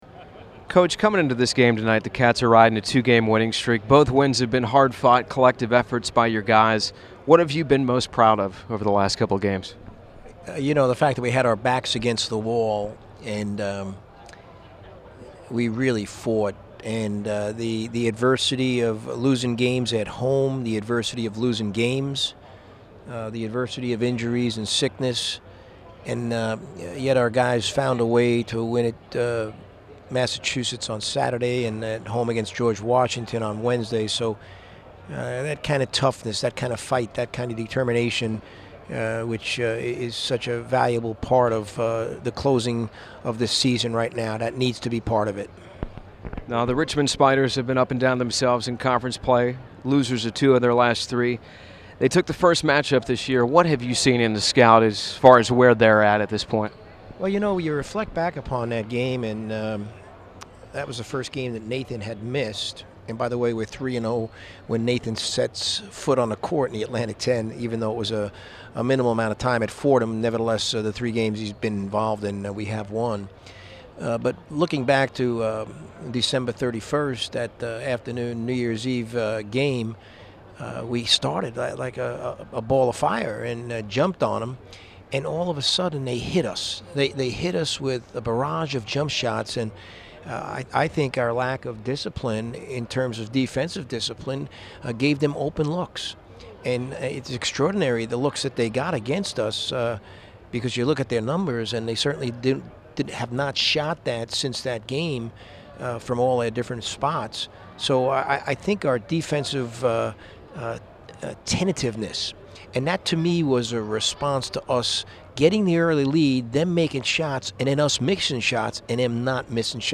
Pregame Radio Interview